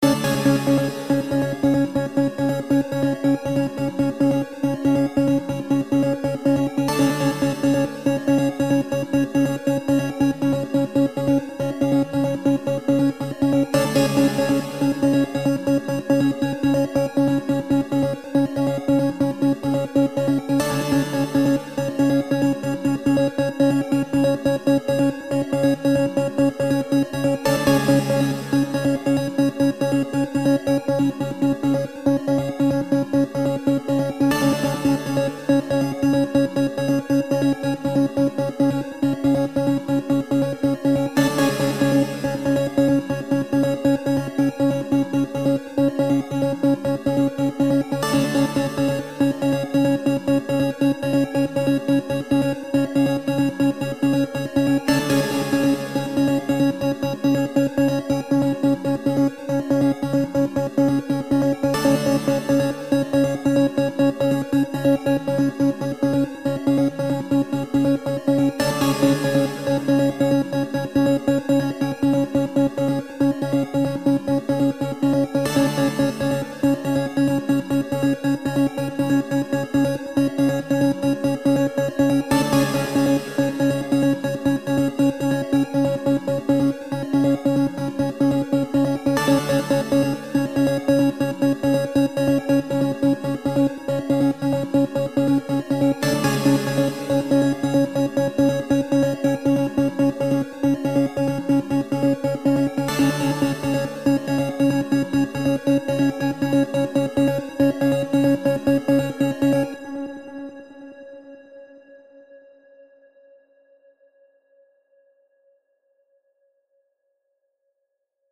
【用途/イメージ】ニュース　メディア　ドキュメント　報道　ナレーション　緊急　事件